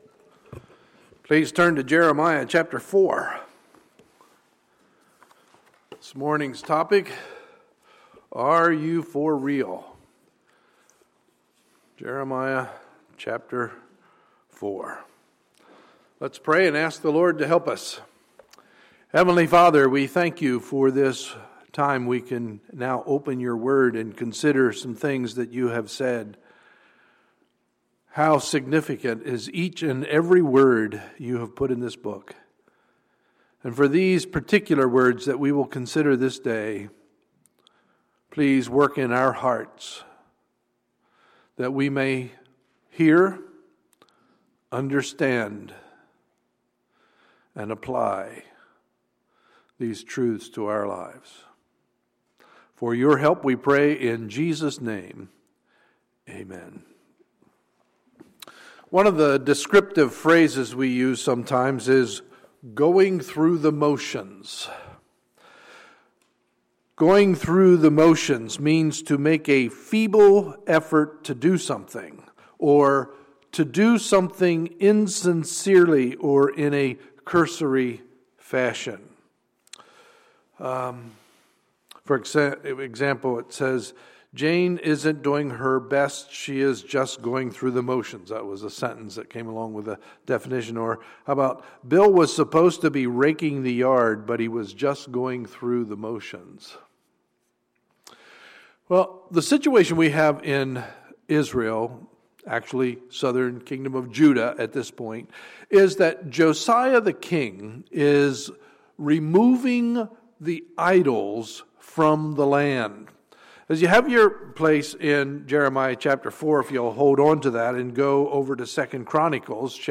Sunday, February 1, 2015 – Sunday Morning Service